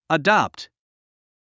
🔤 発音と読み方：/əˈdæpt/
「adapt」はアダプトと読みます。
カタカナにすると「アダプト」ですが、実際の英語では最初の「a」は弱く曖昧に、「dæpt」の部分にアクセントが置かれます。
• 最初の「a」は弱く（「ア」ではなく「ァ」）
• 強く読むのは “dæpt”（「ダプト」に近い音）